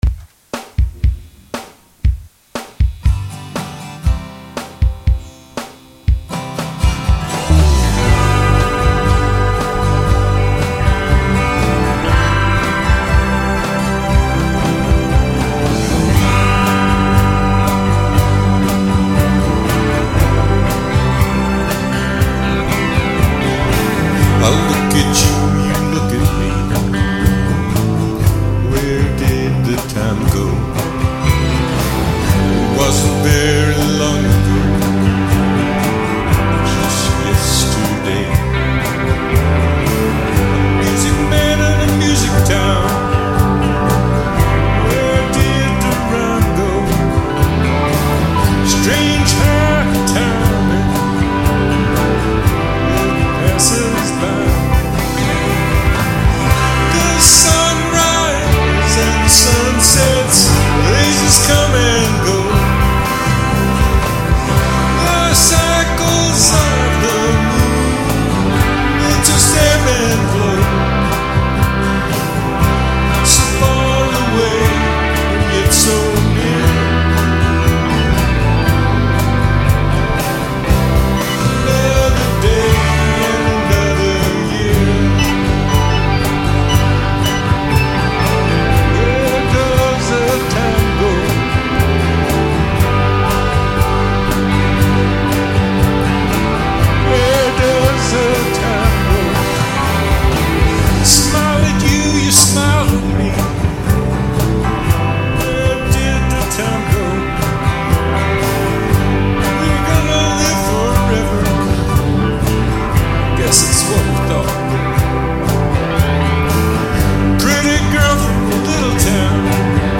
Drums are by me